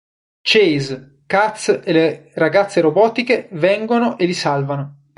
Read more Pron Article Adv Frequency A1 Pronounced as (IPA) /li/ Etymology From Latin illī, nominative masculine plural of ille.